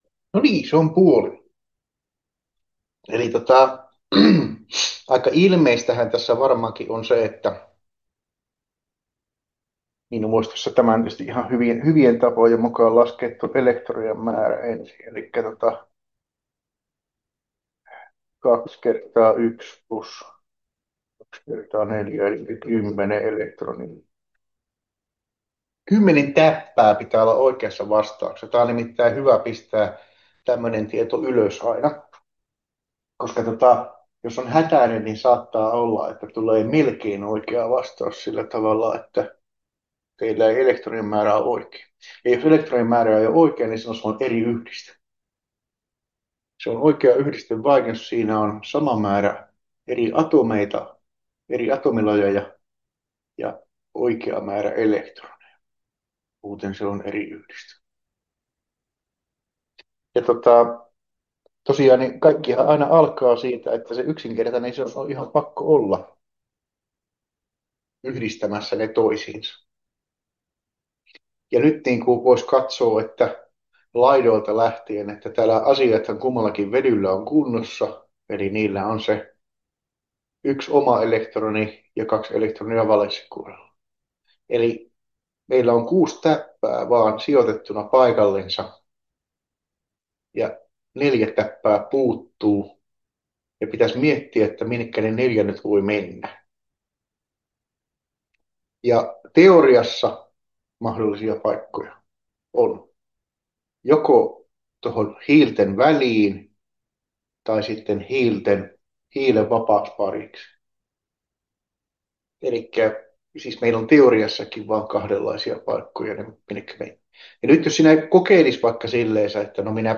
KEMP1131 luento 1 osa 2 — Moniviestin
12.1.2026 Luennon lopusta puuttuu muutama minuutti.